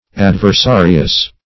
Meaning of adversarious. adversarious synonyms, pronunciation, spelling and more from Free Dictionary.
\Ad`ver*sa"ri*ous\